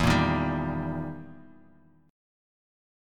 Eb/E chord
Eb-Major-E-0,1,1,0,x,x.m4a